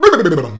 B-B-B-B-OM.wav